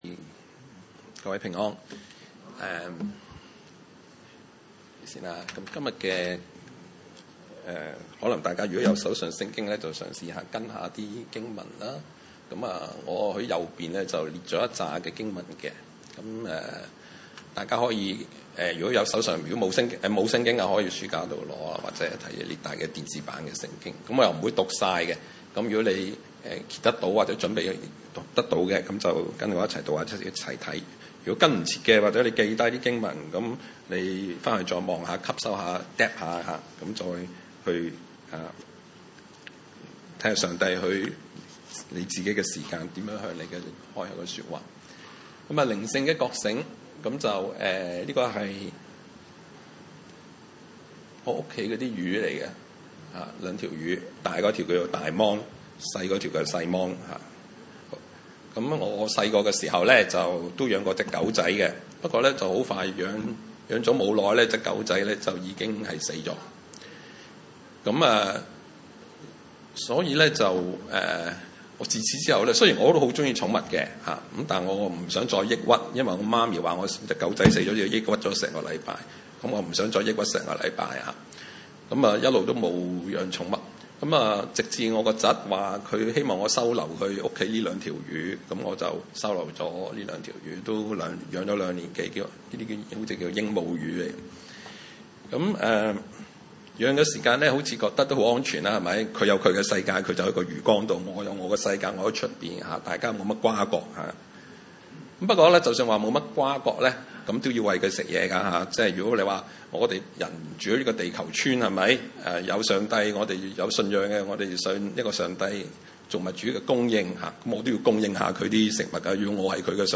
經文: 撒母耳記上3 : 1-10 崇拜類別: 主日午堂崇拜 1 童子 撒母耳 在 以利 面前事奉耶和華。